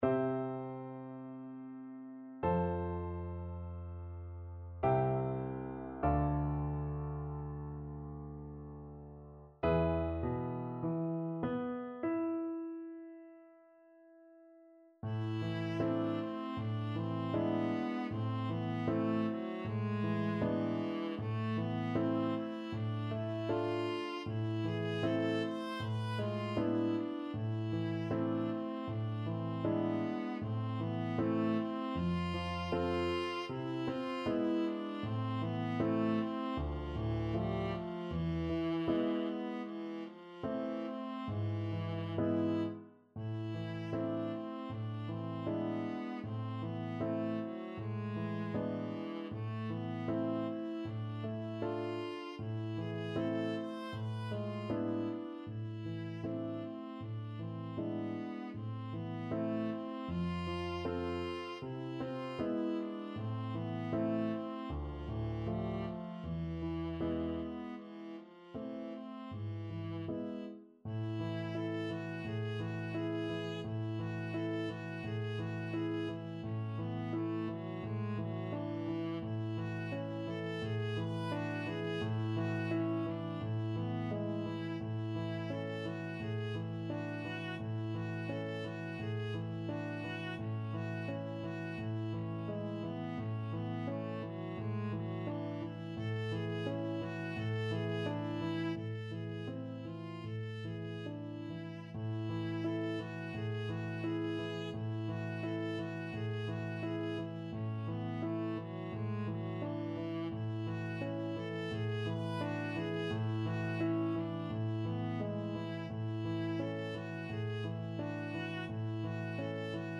Viola
C major (Sounding Pitch) (View more C major Music for Viola )
Largo
Classical (View more Classical Viola Music)